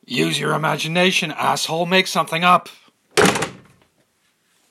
slamming-door.m4a